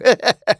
tnt_guy_kill_04.wav